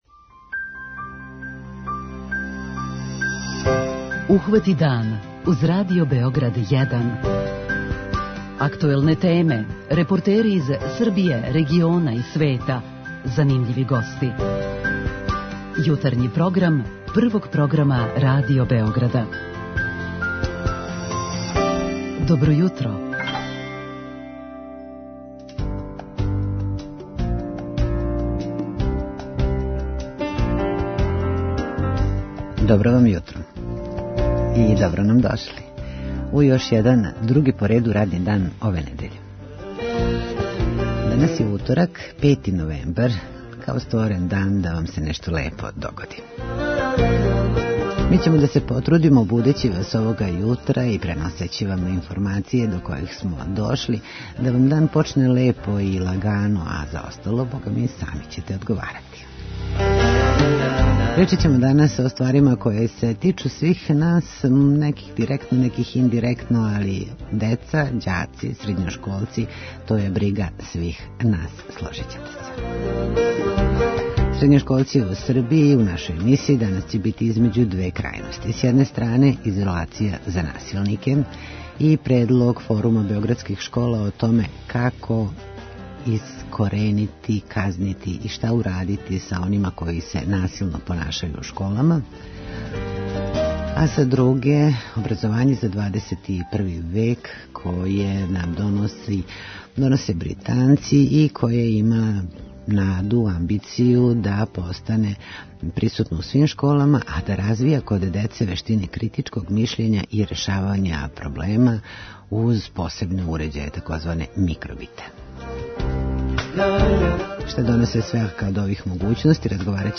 Шта доноси свака од ових могућности, разговарамо са гостима у студију.
Са терена се јављају репортери из Шапца, Прокупља, Пожаревца. Обележићемо и светски Дан Ромског језика и почетак 24. Југословенског позоришног фестивала.